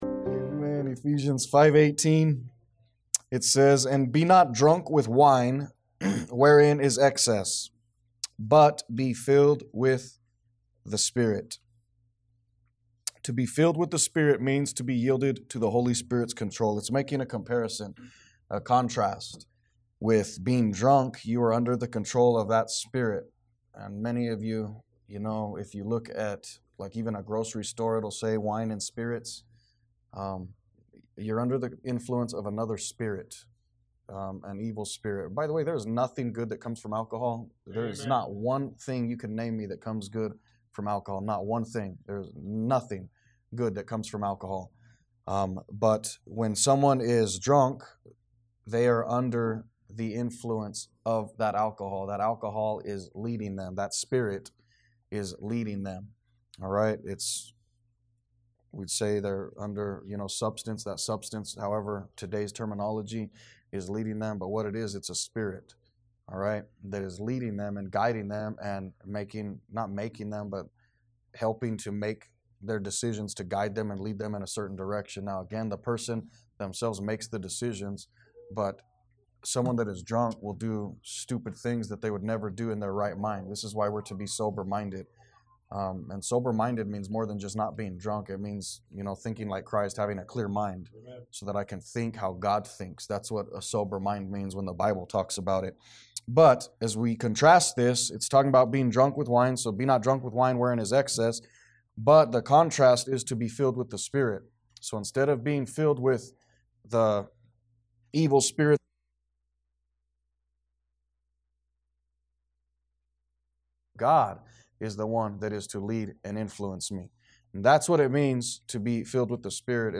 A message from the series "What is a Disciple?."